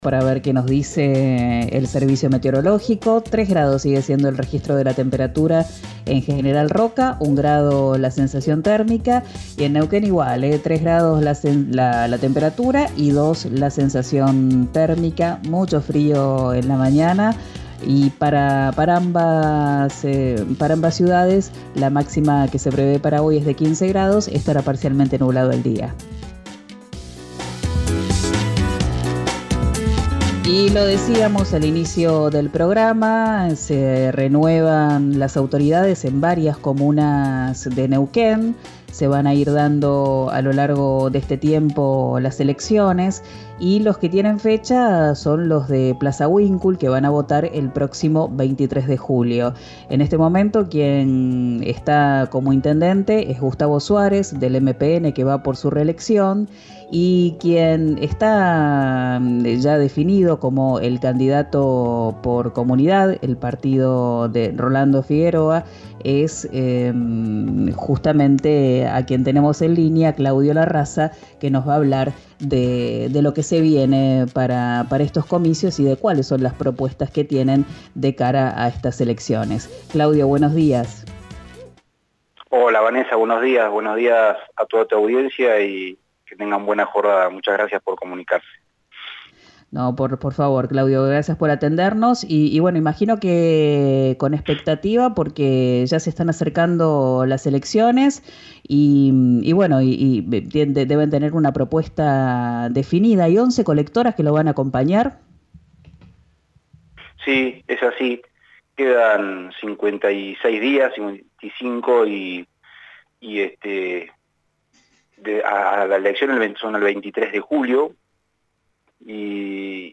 habló con RÍO NEGRO RADIO sobre su programa de gobierno.